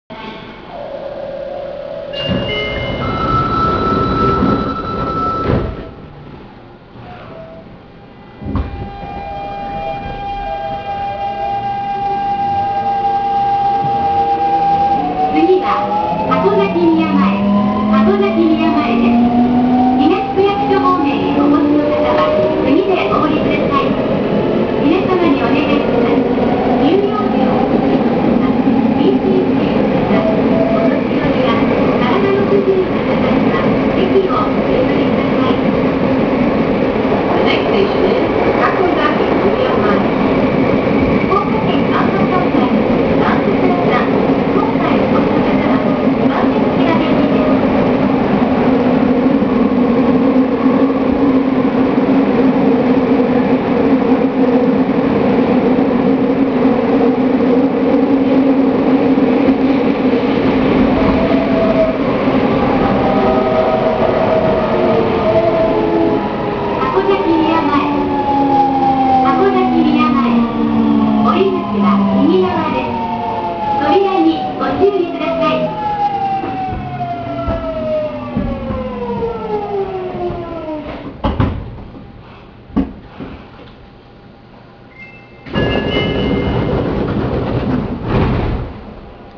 2000系日立GTO走行音
【貝塚線】箱崎九大前〜箱崎宮前（1分29秒：486KB）
24編成を除き、VVVFは日立GTOで、東急2000系、京王8000系、西武6000系等と同系統の音となります。